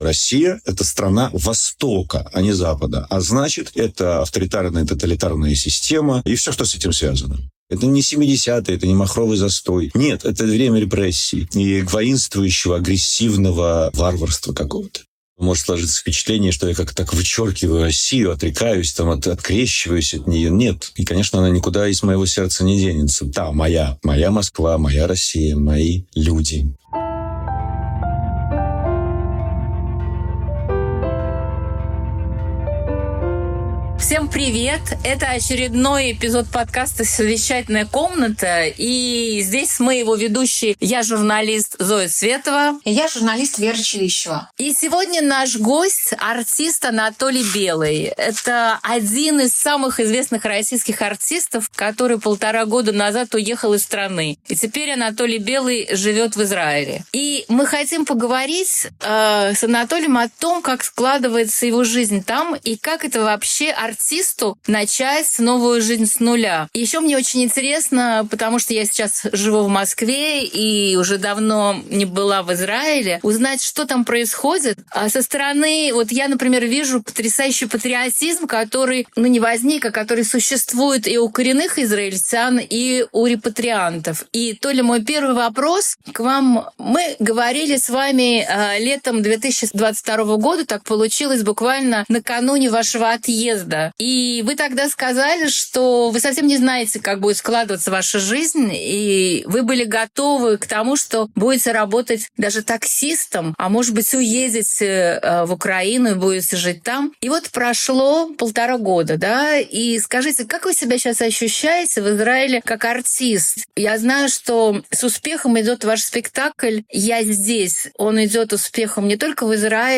Анатолий Белый актер